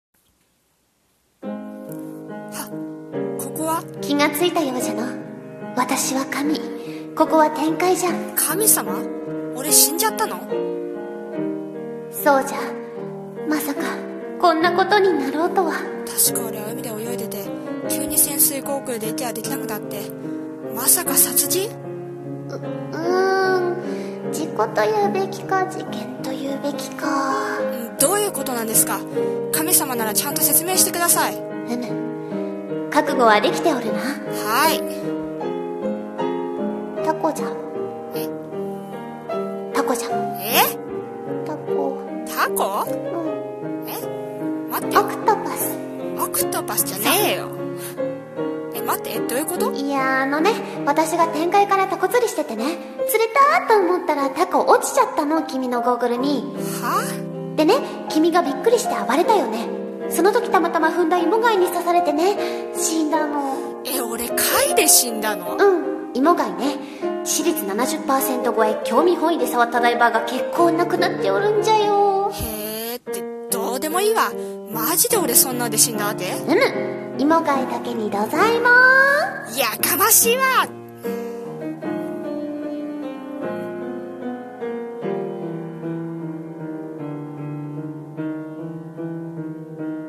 ギャグ声劇】突然の死（前編）【掛け合い